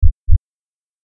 Heartbeat.mp3